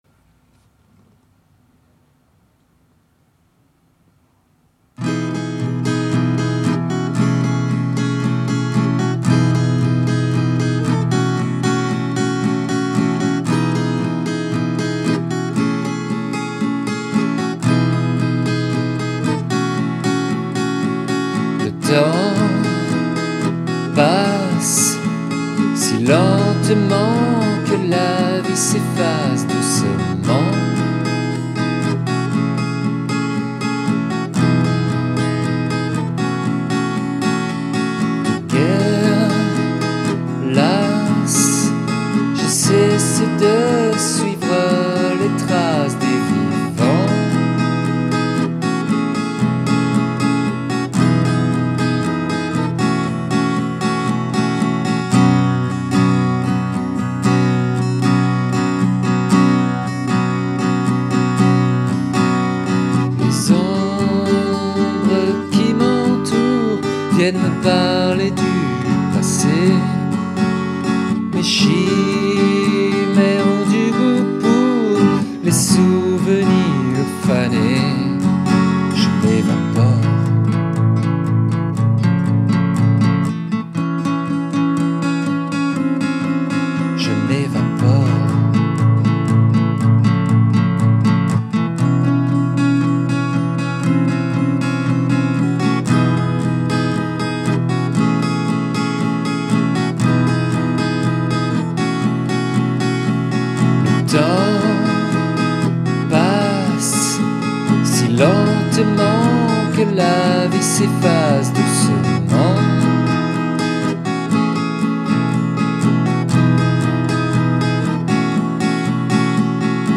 live acoustique de 2008